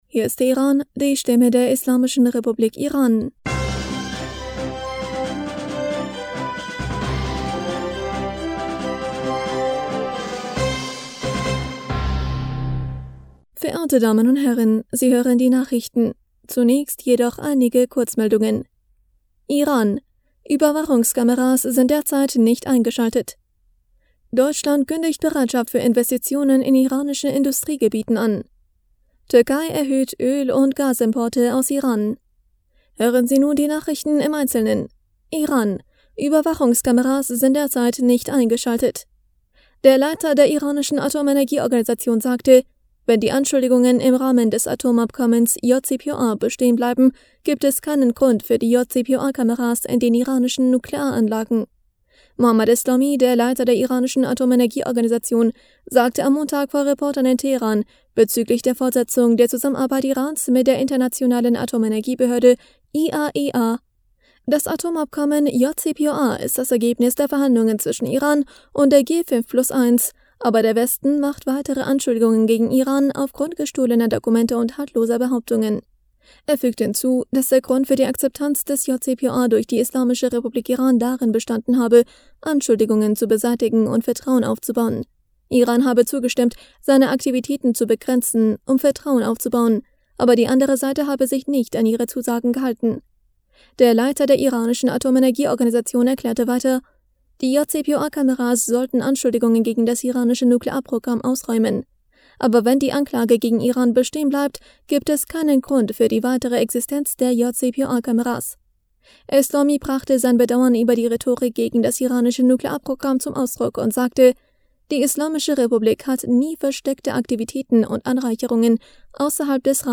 Nachrichten vom 26. Juli 2022
Die Nachrichten von Dienstag, dem 26. Juli 2022